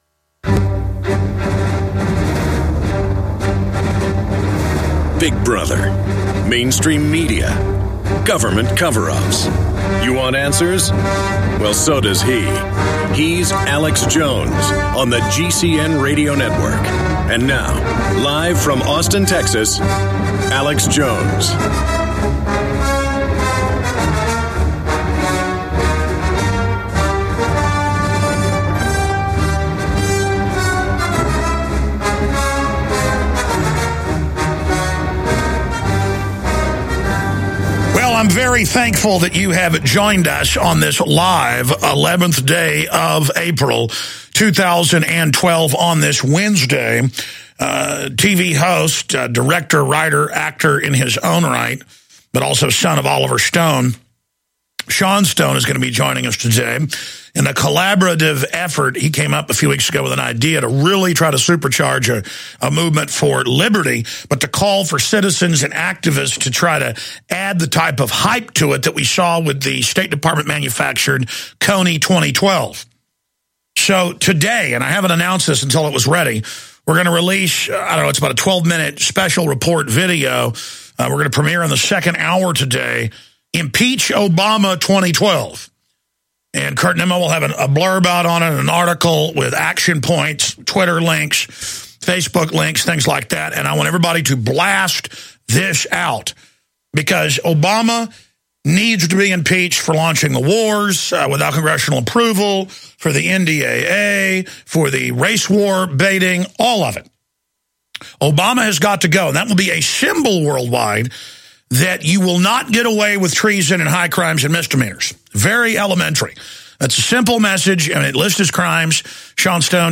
Alex Jones Show Commercial Free Podcast
Watch Alex's live TV/Radio broadcast.